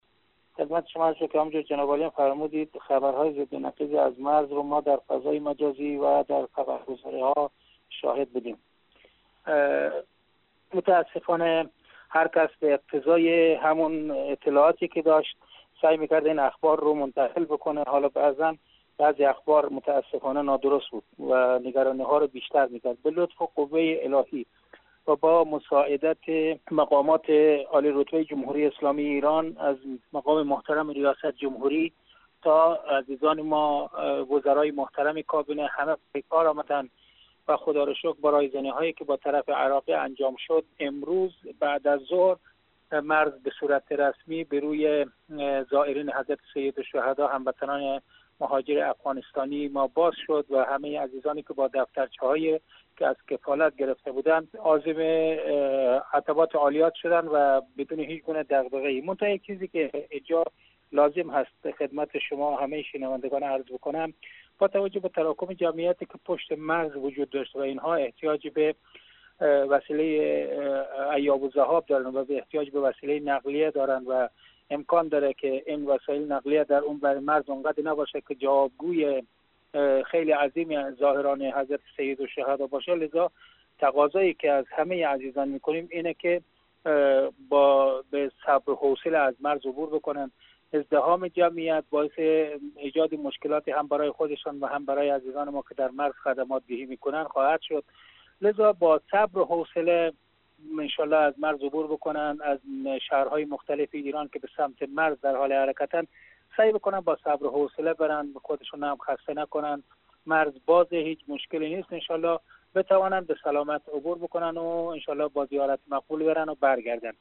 در مصاحبه با رادیو دری